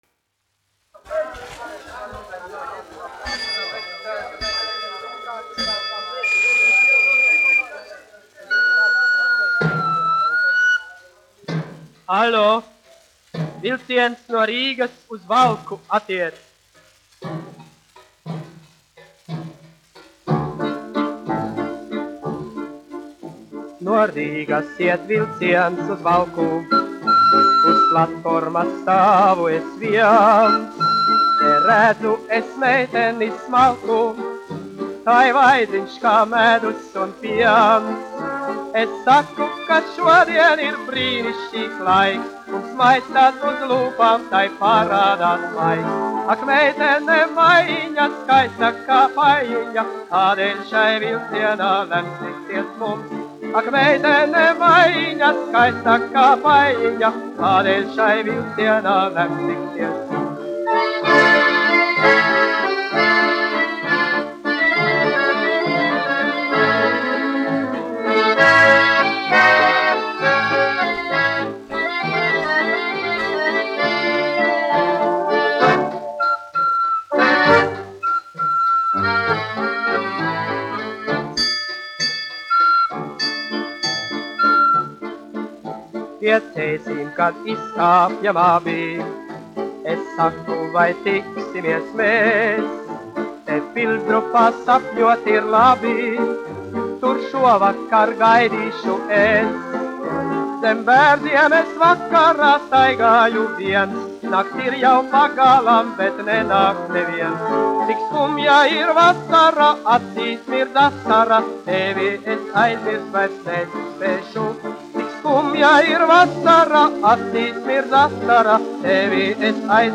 1 skpl. : analogs, 78 apgr/min, mono ; 25 cm
Populārā mūzika